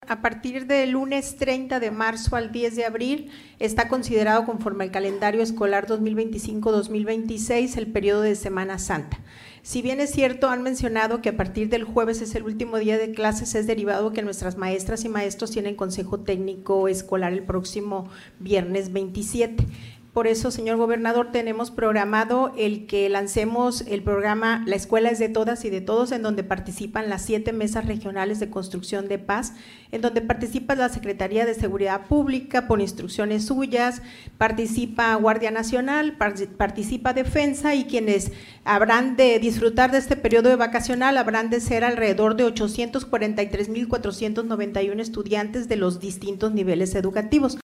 Culiacán, Sinaloa, a 23 de marzo de 2026.- Durante la conferencia de prensa “Semanera”, el gobernador del Estado, Dr. Rubén Rocha Moya, junto a la secretaria de Educación Pública y Cultura, Lic. Gloria Himelda Félix Niebla, informaron que, del 30 de marzo al 10 de abril, un total de 843 mil 491 estudiantes de todos los niveles educativos disfrutarán del periodo vacacional de Semana Santa, de acuerdo con el Calendario Escolar 2025-2026 vigente.